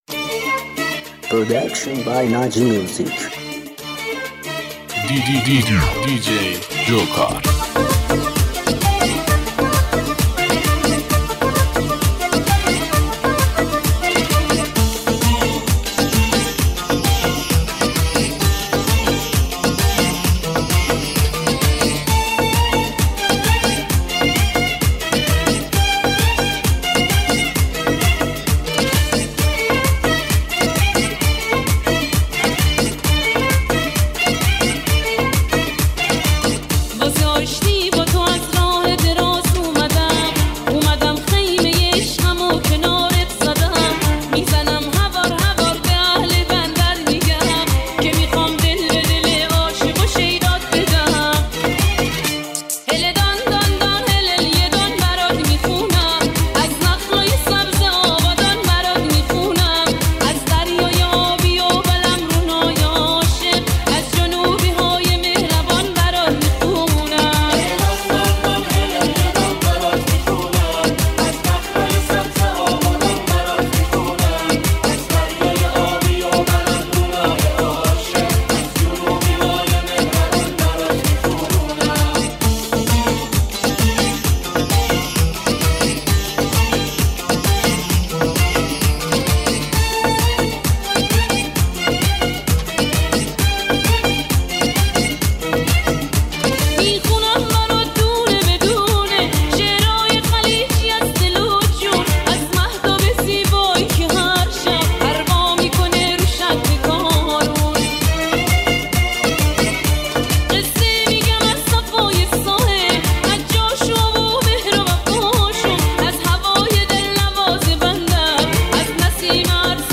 با صدای زن ریمیکس شاد